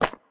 step-1.wav